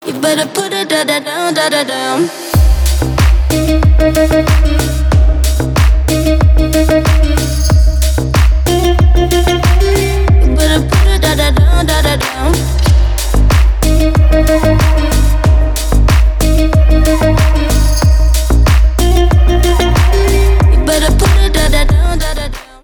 • Качество: 320, Stereo
Electronic
EDM
басы
Стиль: deep house